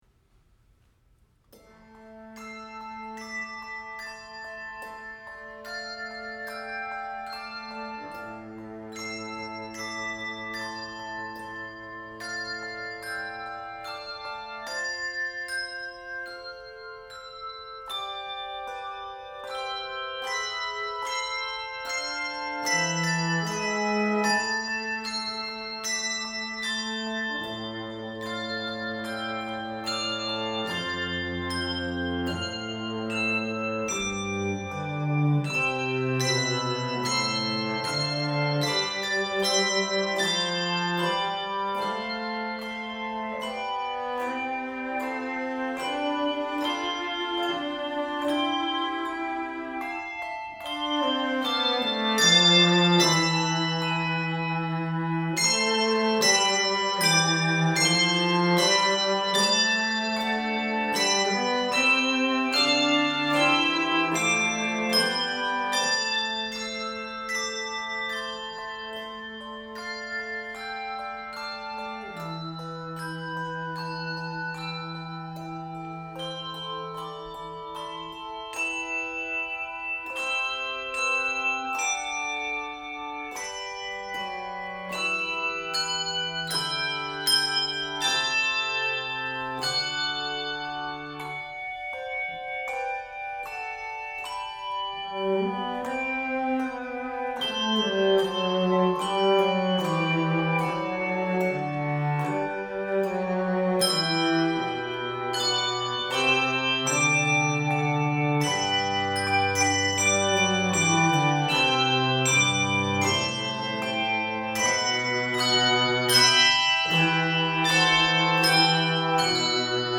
Voicing: optional cello part